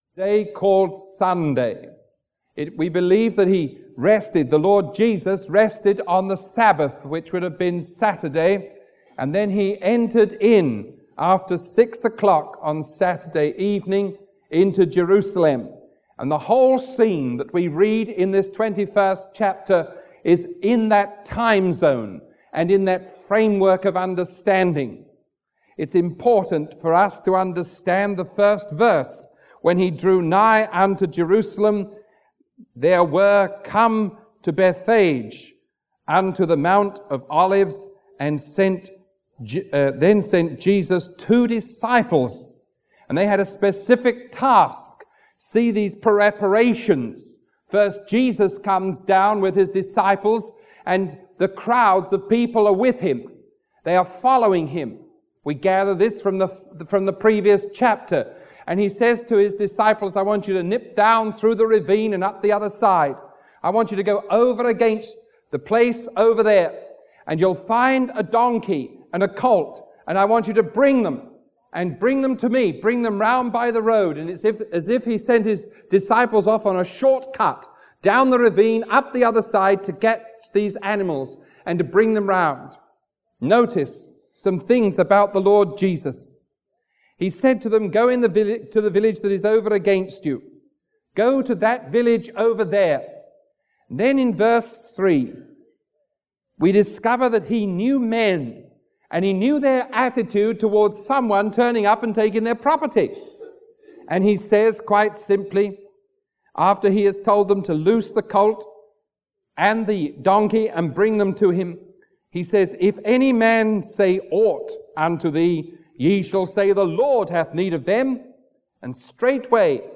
Sermon 0519A recorded on April 4